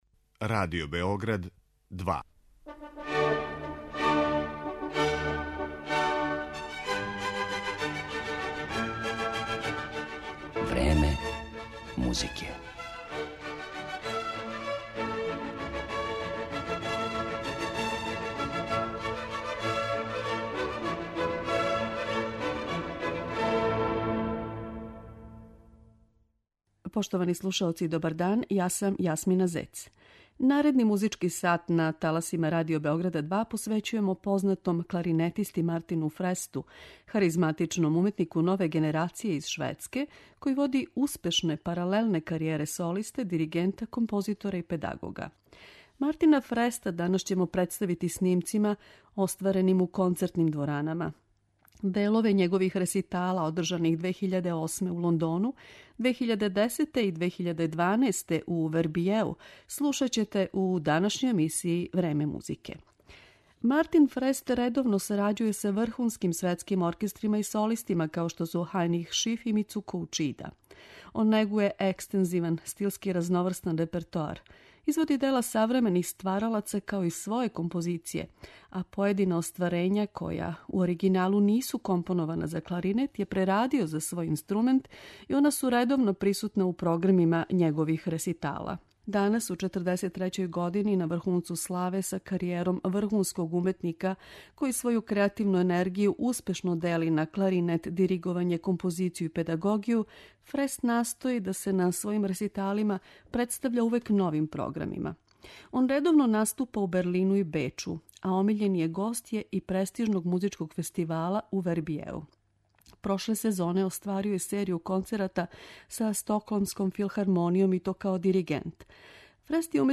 Слушаоци ће моћи да чују његове снимке остварене у концертним дворанама - делове реситала које је одржао у Лондону и у оквиру престижног музичког фестивала у Вербијеу.